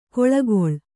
♪ koḷagoḷ